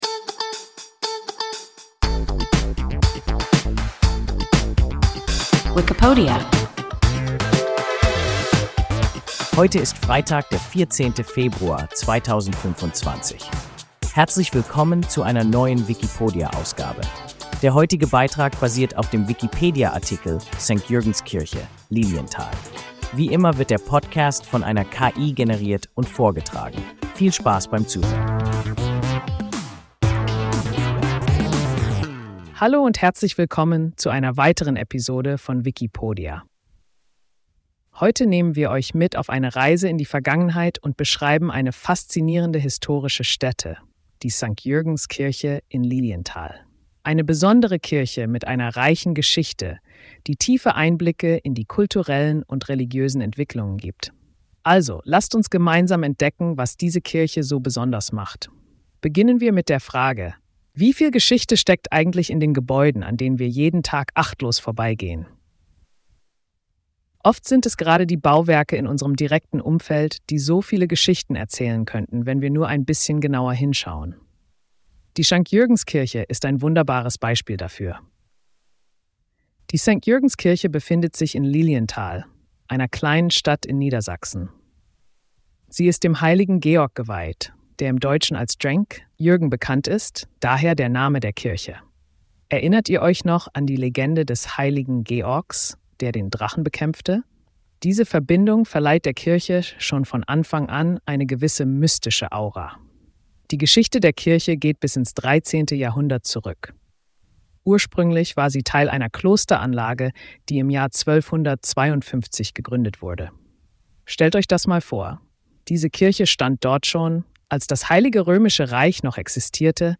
St.-Jürgens-Kirche (Lilienthal) – WIKIPODIA – ein KI Podcast